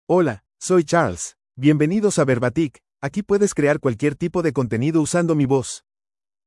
Charles — Male Spanish (United States) AI Voice | TTS, Voice Cloning & Video | Verbatik AI
MaleSpanish (United States)
Voice sample
Male
Charles delivers clear pronunciation with authentic United States Spanish intonation, making your content sound professionally produced.